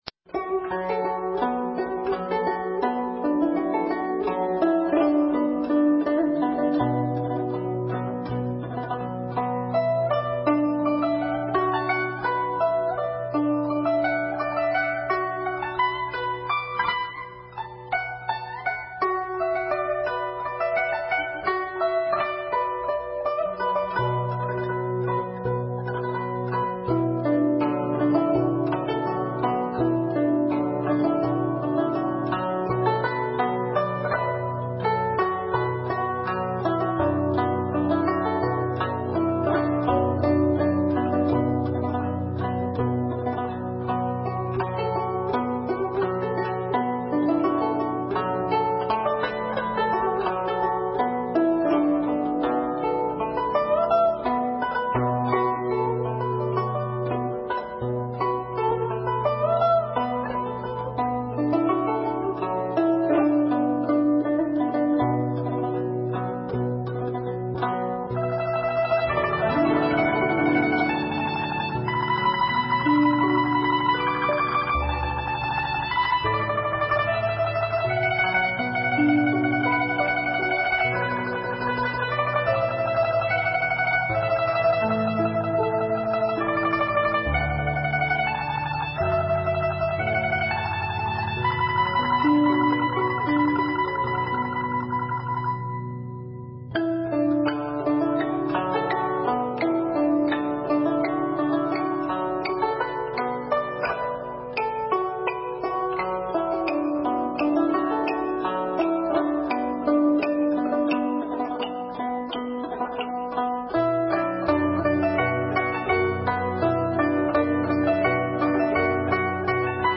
佛音 诵经 佛教音乐 返回列表 上一篇： 给孤独夫妇 下一篇： 舍身饲虎 相关文章 放下(伴奏版